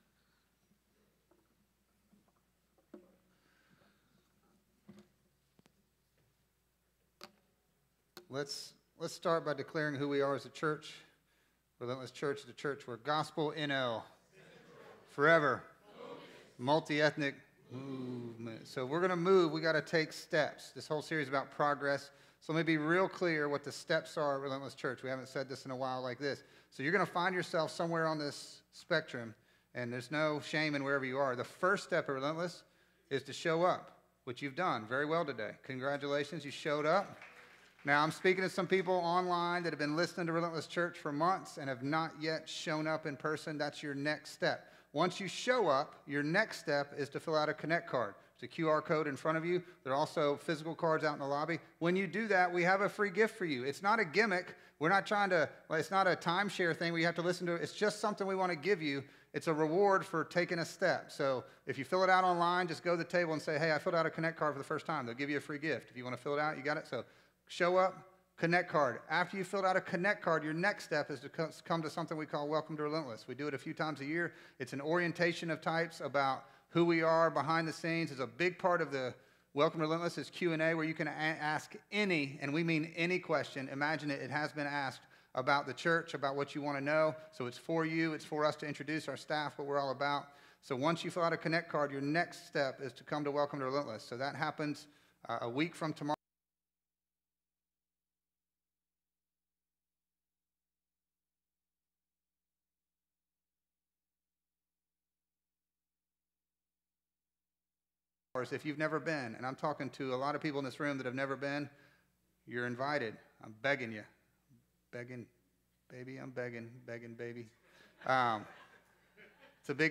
A message from the series "Progress."